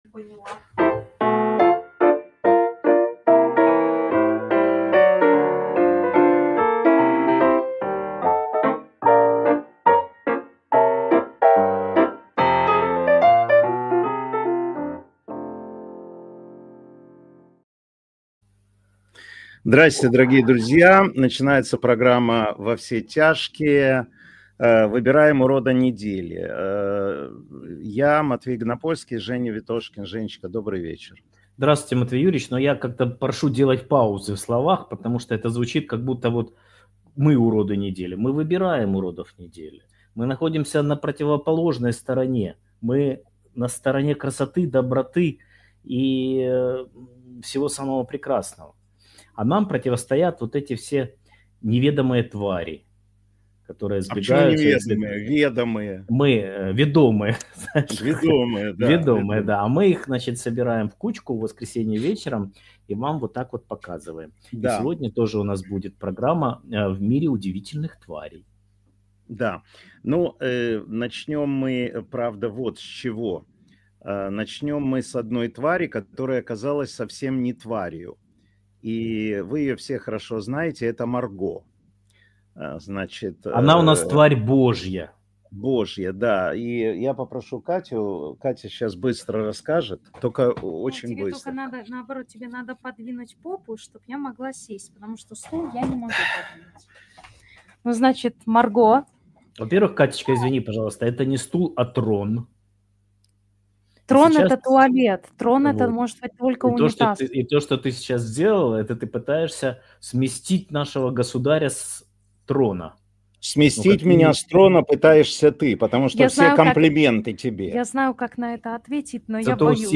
Эфир ведёт Матвей Ганапольский